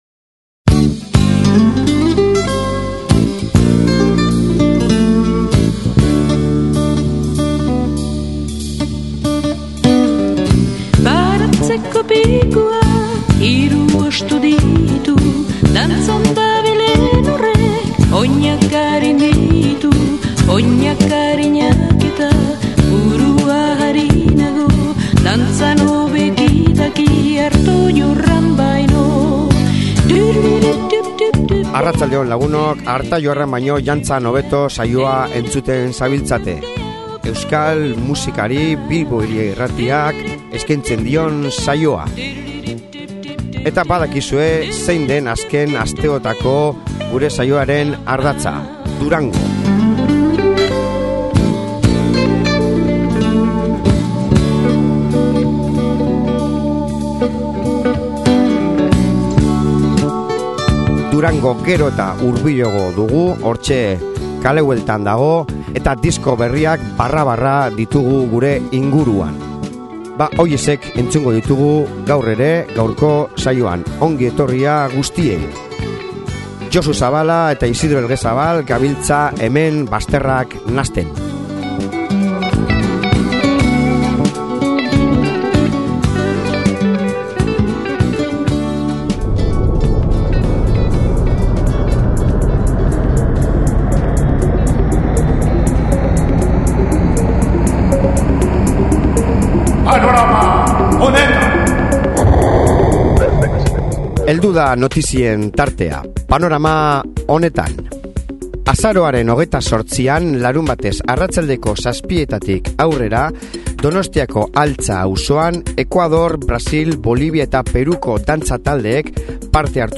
Kantu pupurria dakargu gaur disko berriekin eta denetik